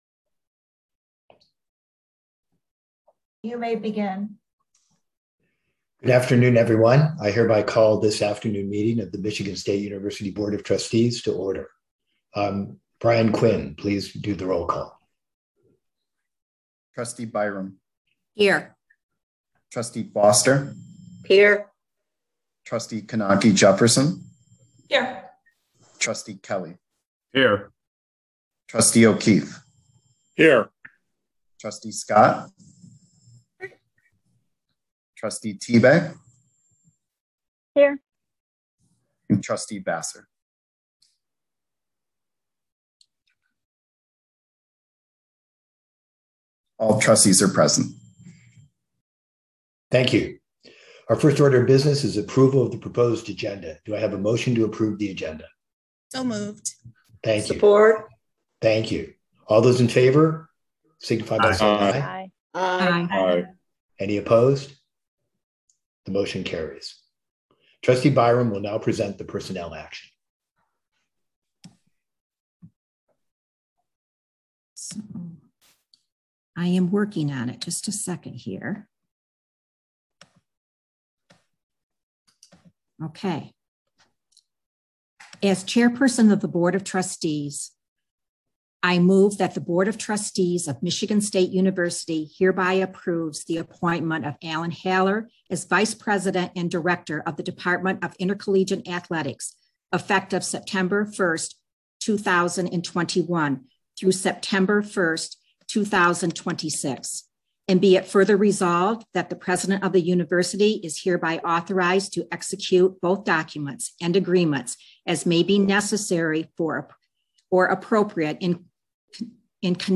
When: 12:00 noon Where: Via Zoom September 1, 2021 Audio Recording (.mp3) September 1, 2021 Board Meeting Minutes Agenda Call to Order Approval of Proposed Agenda Personnel Action Trustee Comments Adjourn Click here to find past meeting resolutions in the Resolution Tracker .